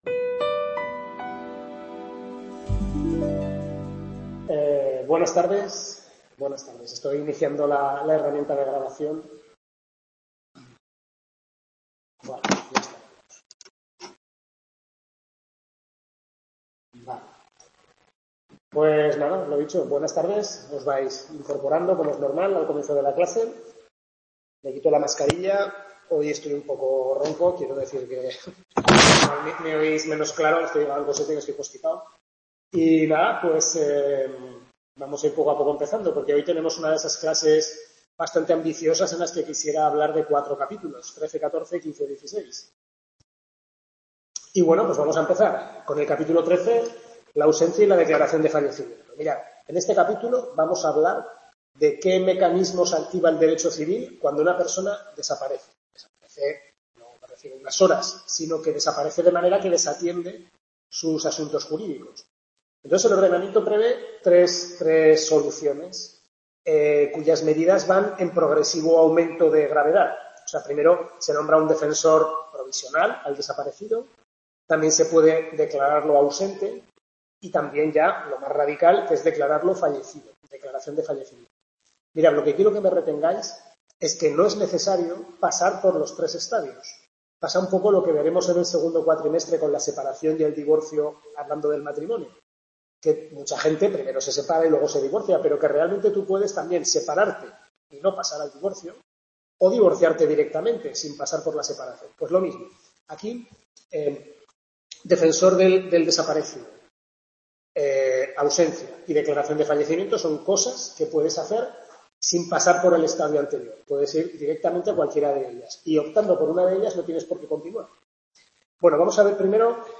Tutoría de Civil I primer cuatrimestre